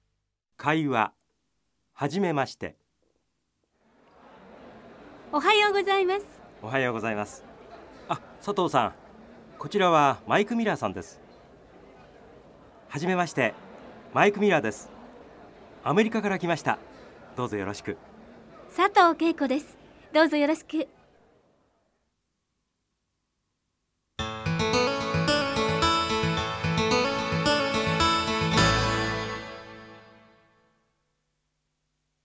会話（かいわ）Bài đàm thoại